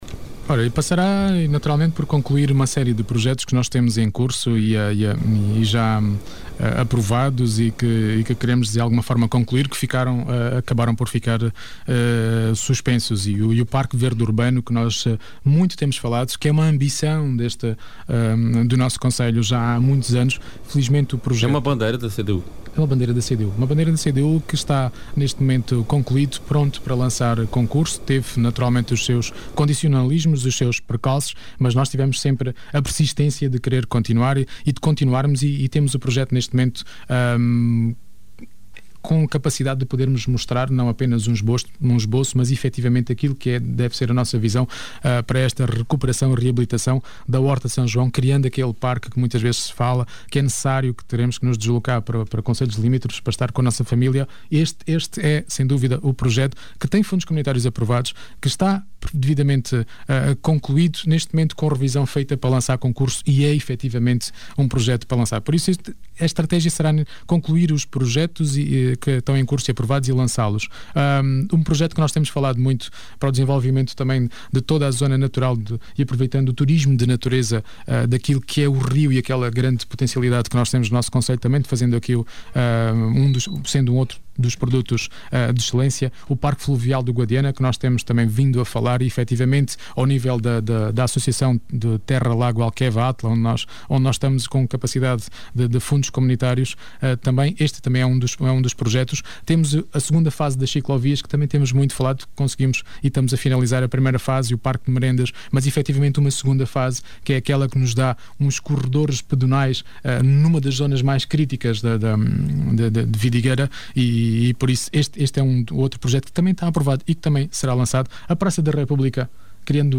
O cabeça de lista da CDU, em entrevista na Rádio Vidigueira, no programa À Conversa Com, da passada sexta-feira, afirma que a “estratégia e o rumo” estão definidos desde o inicio do mandato, e passa por “promover e divulgar o território” e “atrair investimentos e fixar pessoas”.